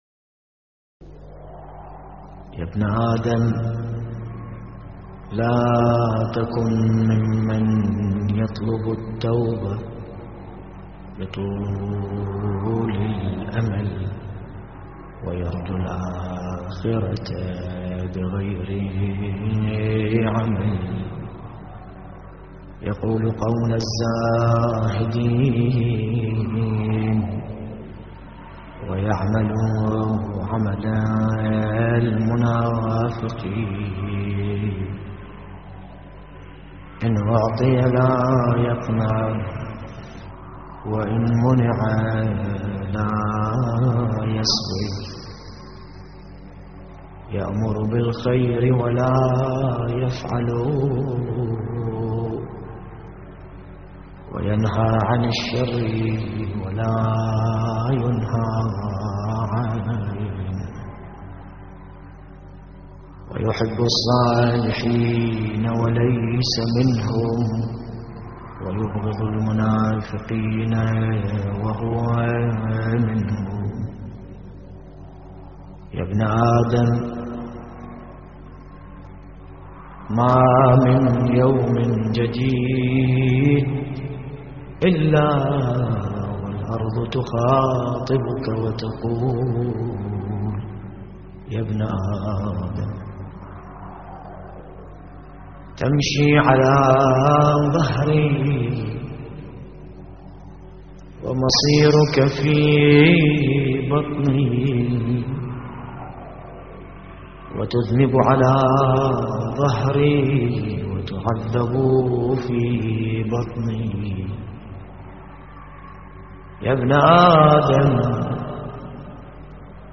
تحميل : يا ابن آدم لا تكن ممن يطلب التوبة بطول الأمل / الرادود باسم الكربلائي / اللطميات الحسينية / موقع يا حسين
موعظة